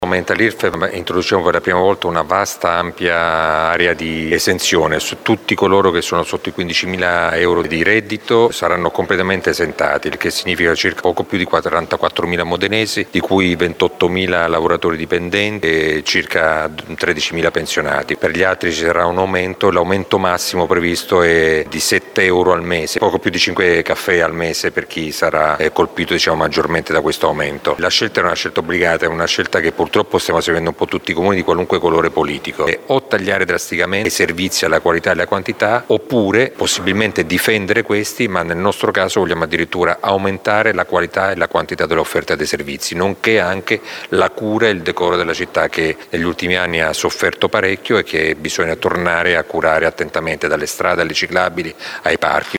Qui sotto potete ascoltare le parole del sindaco Massimo Mezzetti